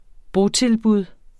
Udtale [ ˈbo- ]